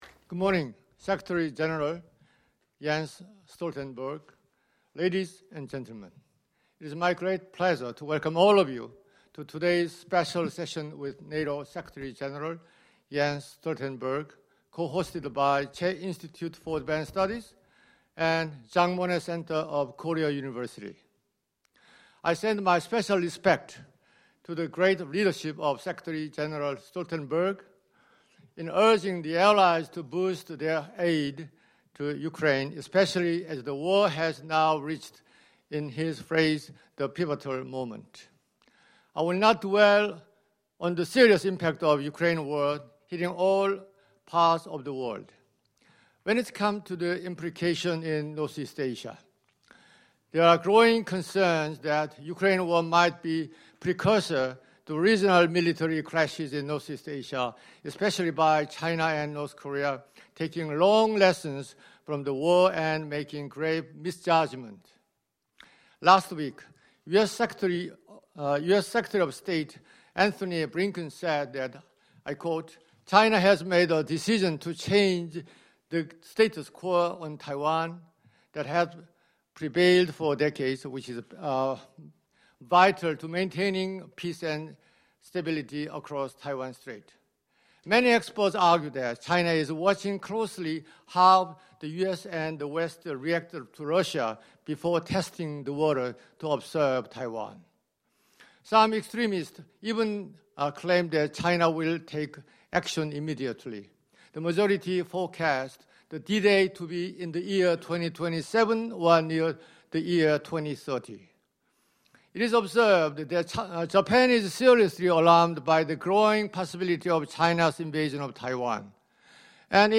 Remarks
by NATO Secretary General Jens Stoltenberg at the CHEY Institute during his visit to the Republic of Korea